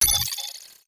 Robotic Game Notification 8.wav